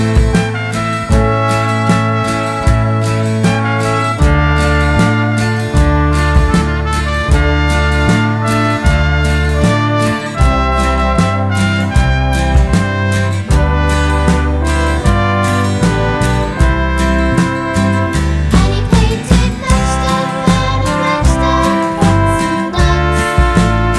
For Duo Pop (1970s) 4:06 Buy £1.50